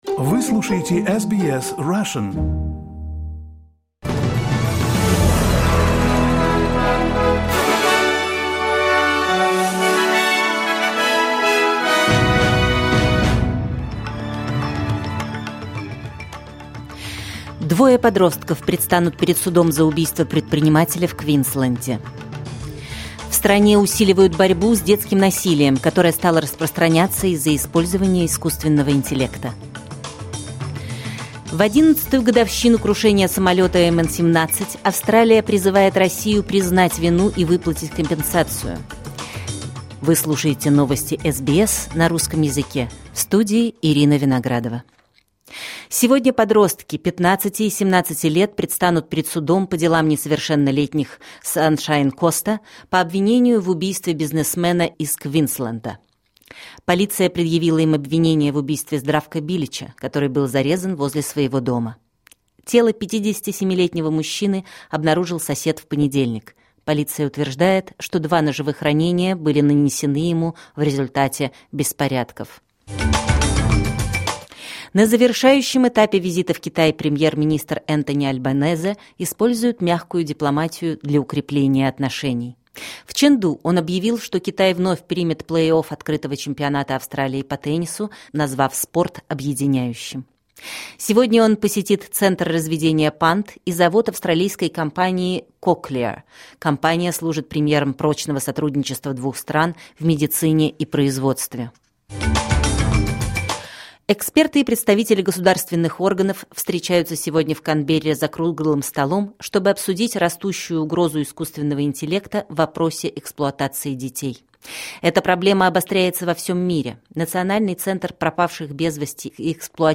Новости SBS на русском языке — 17.07.2025